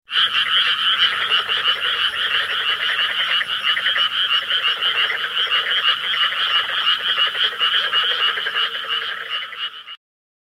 カエル（164KB）